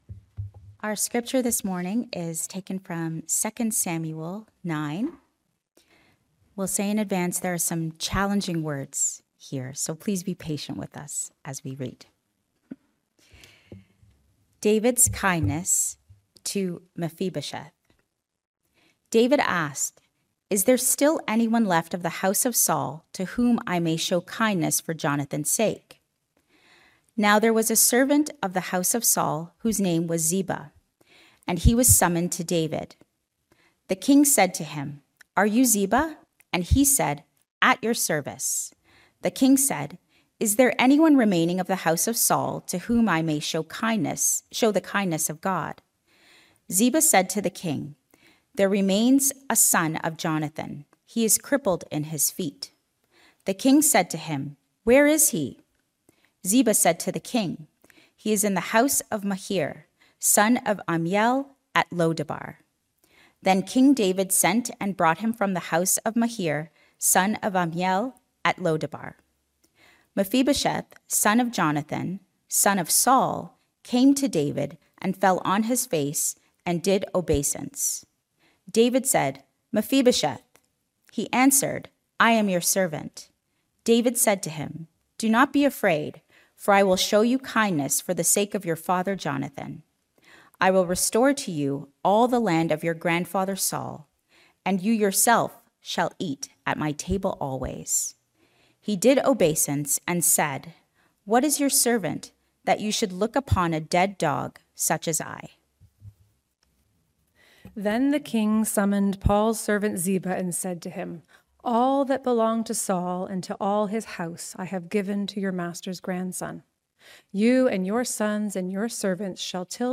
Sermons | Weston Park Baptist Church